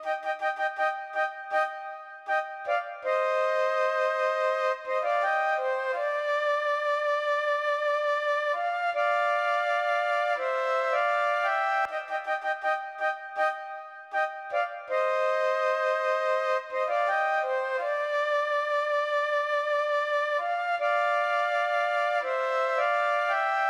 06 flute 1A.wav